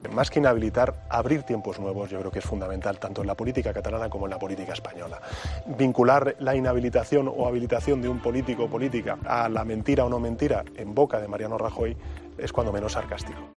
Sánchez ha recordado, en una entrevista en Cuatro, que Rajoy le dijo a los ciudadanos en campaña electoral que no subiría los impuestos y que después los subió y que también prometió luchar contra la corrupción, cuando en realidad "la ha pochado a fuego lento, largamente".